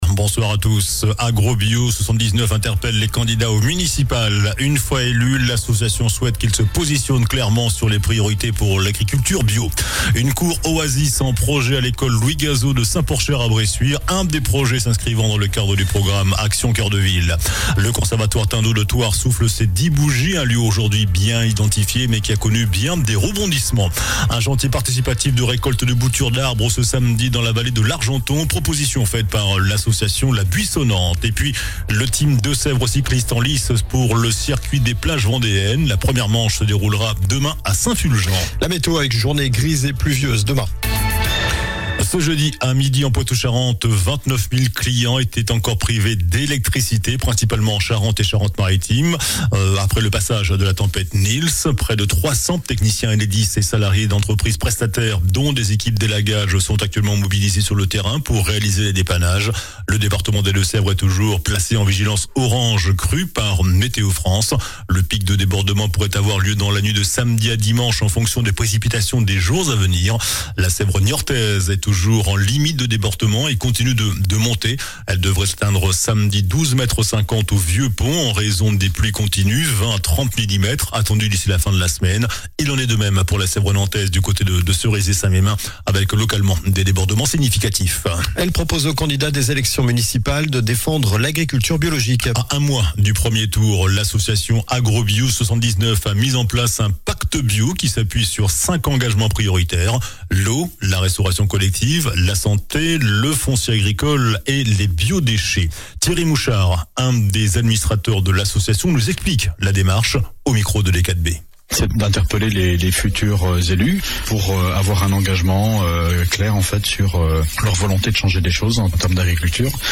JOURNAL DU JEUDI 12 FEVRIER ( SOIR )